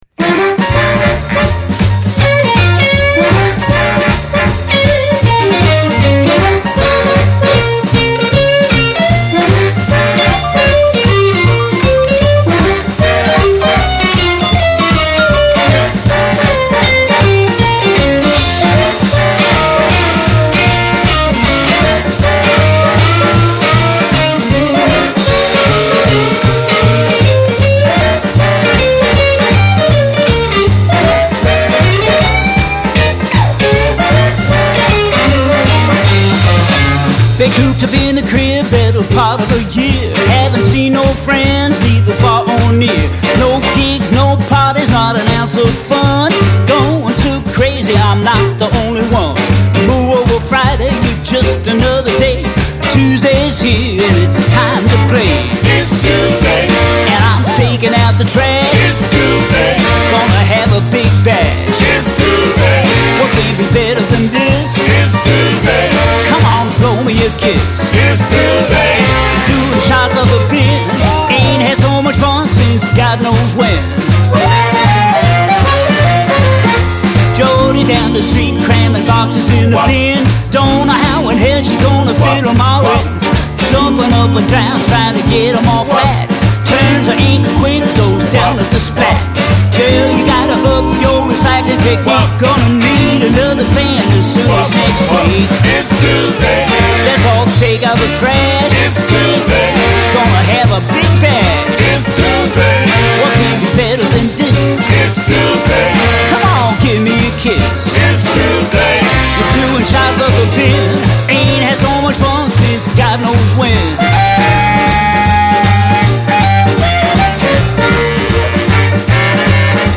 Жанр: Electric Blues